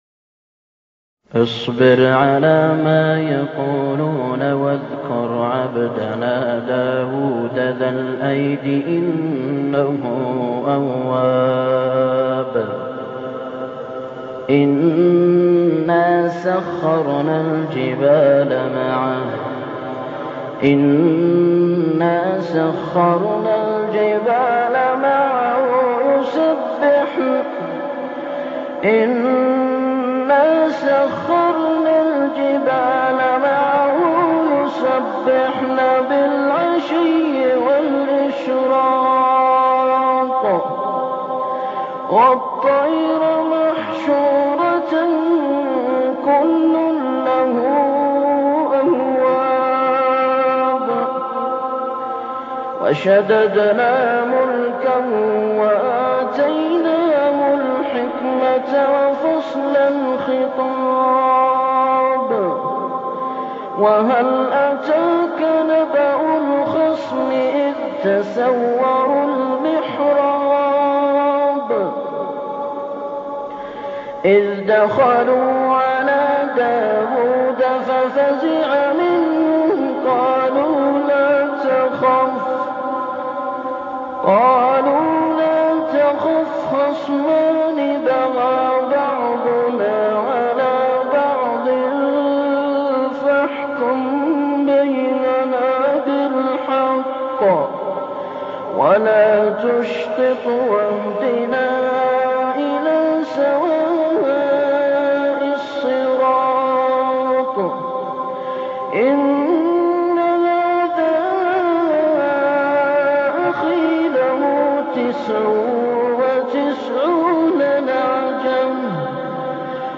قراءة مميزة من سورة ص بصوت